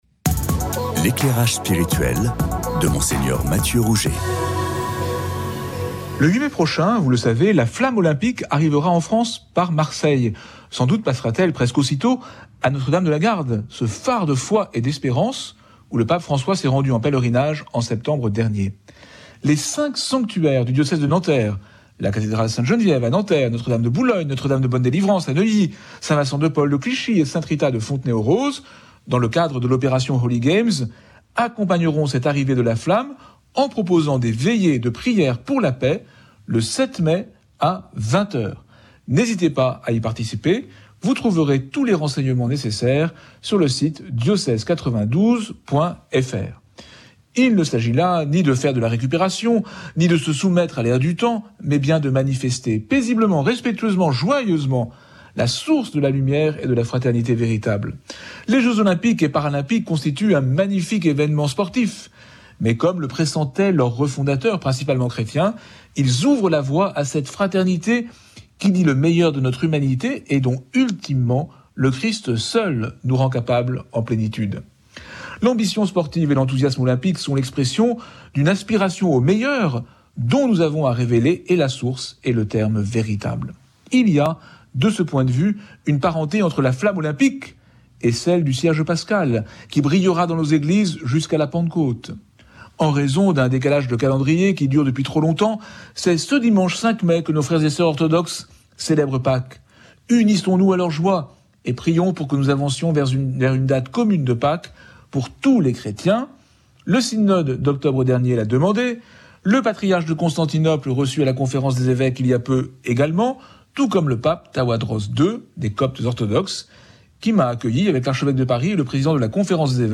éclairage spirituel sur Radio Notre Dame le 3 Mai 2024